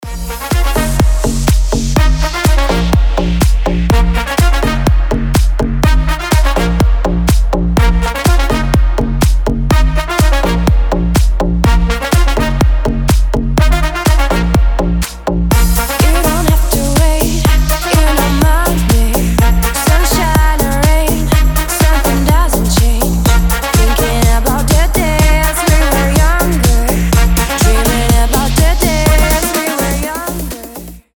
• Качество: 320, Stereo
громкие
женский голос
Club House
энергичные
труба